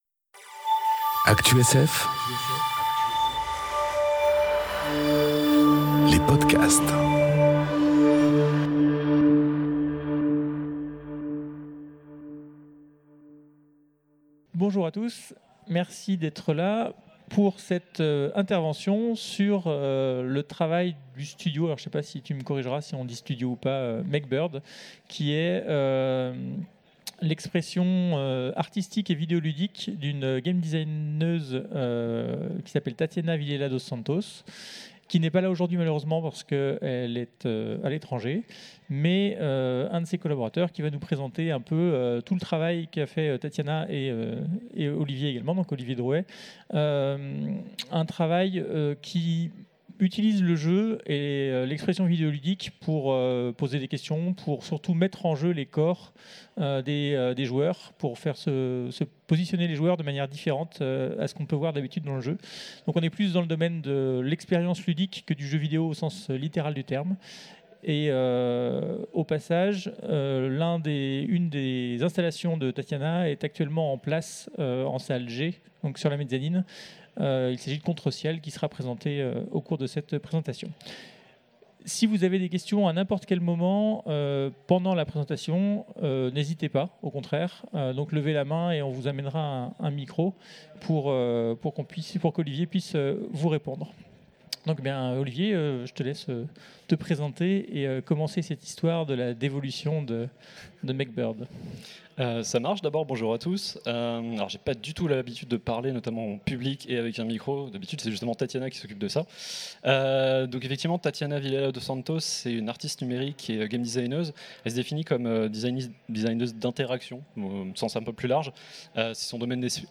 Conférence MechBird, le corps en jeu enregistrée aux Utopiales 2018